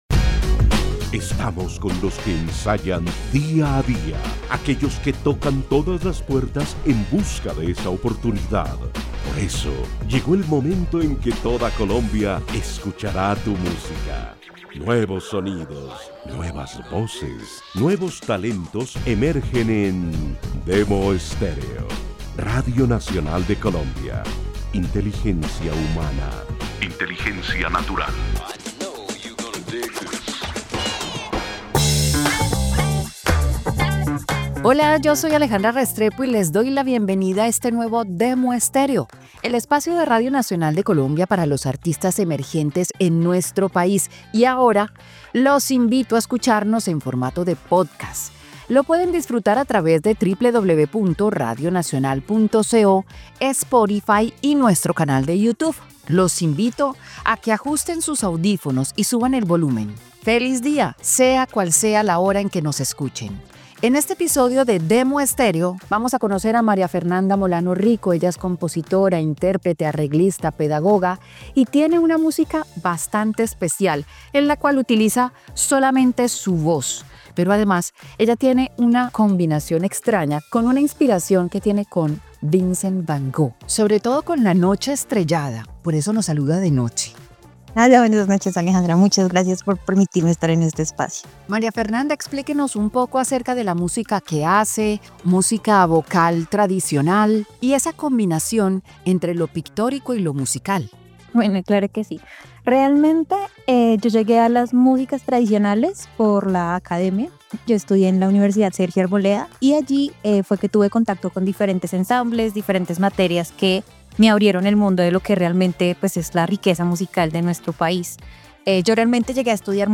la cantante y compositora bogotana que con su voz como único instrumento explora ritmos tradicionales colombiano e inspira su obra en “La noche estrellada” del pintor neerlandés Vincent Van Gogh.